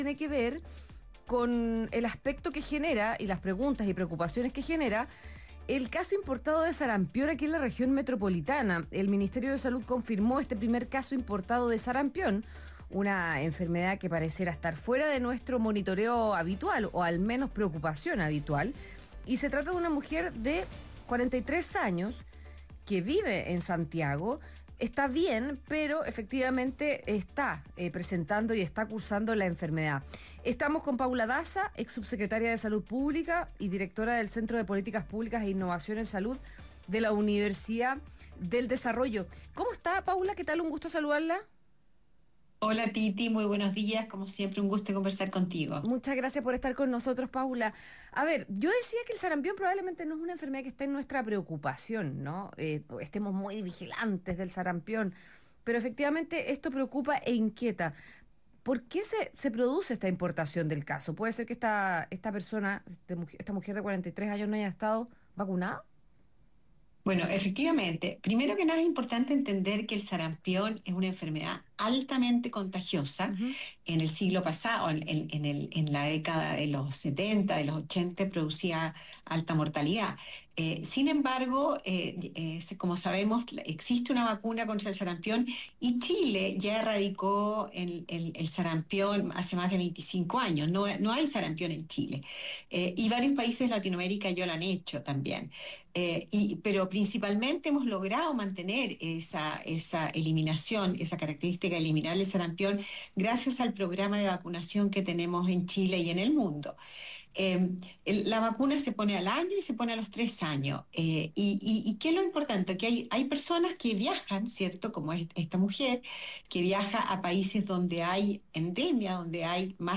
La directora ejecutiva de CIPS UDD, Paula Daza, conversó con Radio Agricultura y advirtió que el sarampión no es una enfermedad que usualmente preocupe, pero este caso importado genera inquietud ya que la persona afectada podría no haber recibido la vacuna, lo que la hace altamente contagiosa.